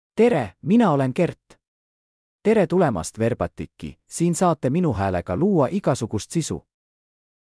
KertMale Estonian AI voice
Kert is a male AI voice for Estonian (Estonia).
Voice sample
Listen to Kert's male Estonian voice.
Male